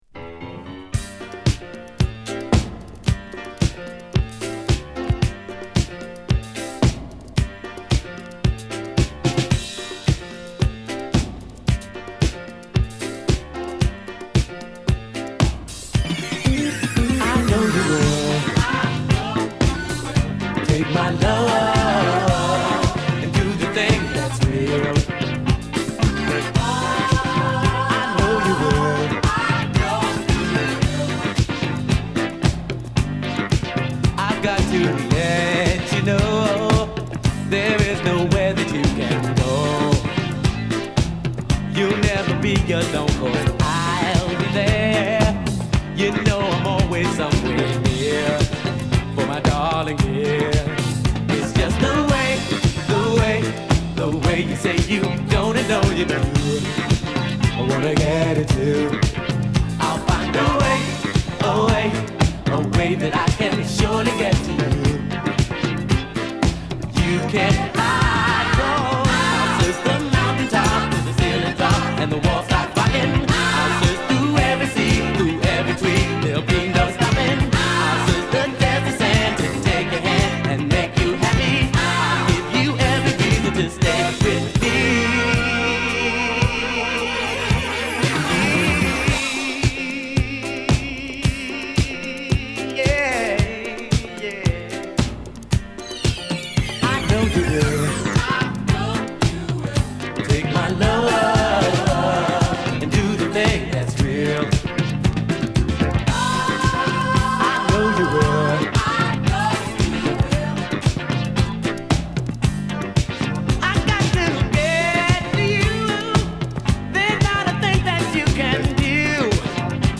こみ上げるメロディにのせる伸びやかなボーカルが最高の1枚！